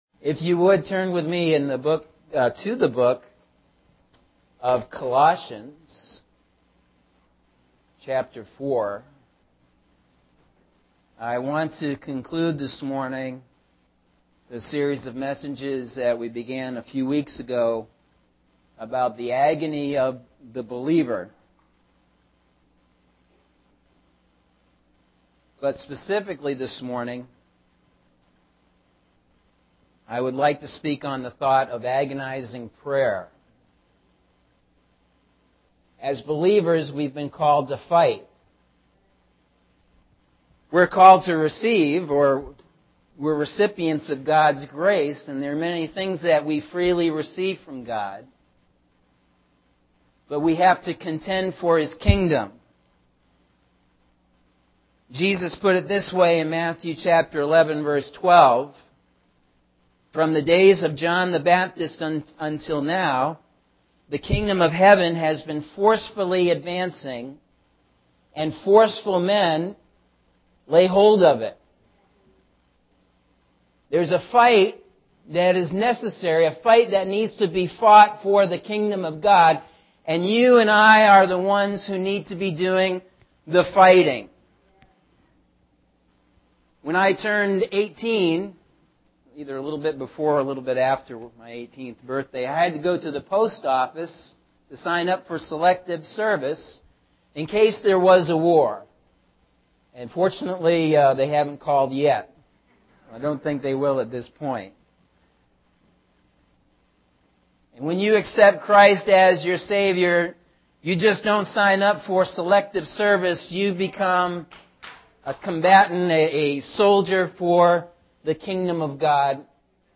Sunday September 8th – AM Sermon – Norwich Assembly of God